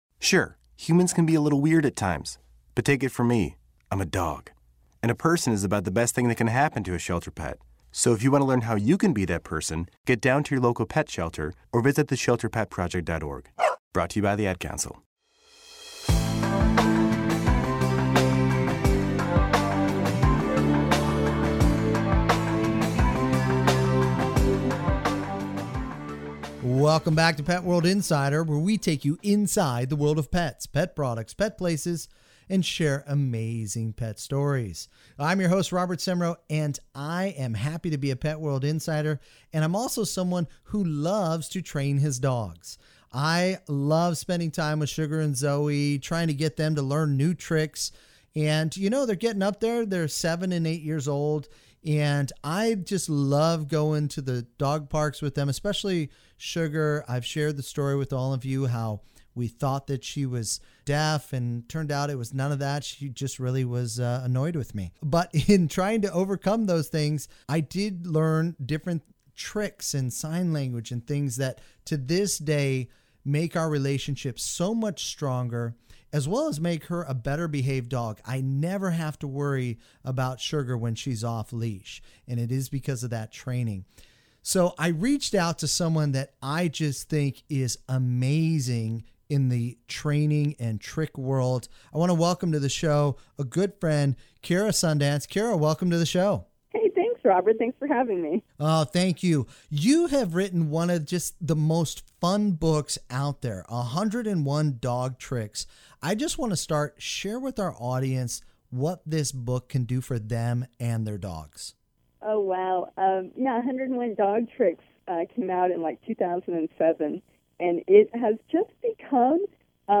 PWI News ,PWI Radio